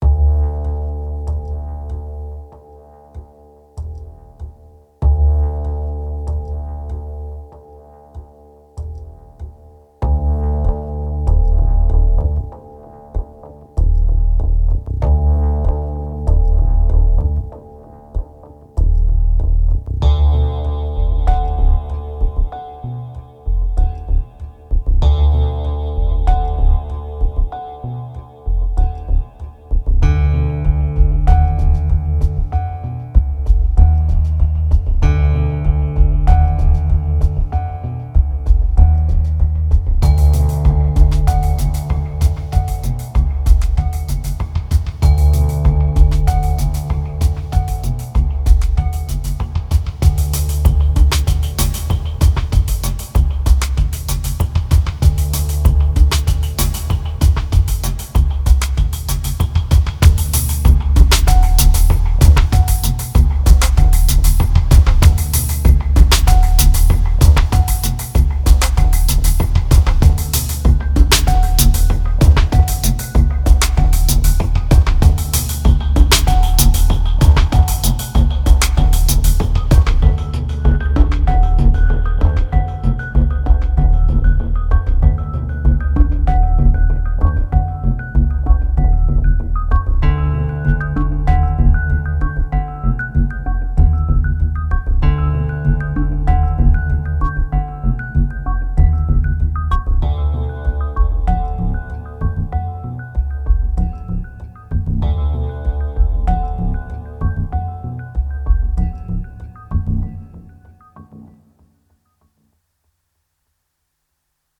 2280📈 - -2%🤔 - 96BPM🔊 - 2010-03-13📅 - -139🌟